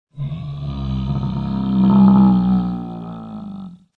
descargar sonido mp3 rugido dinosaurio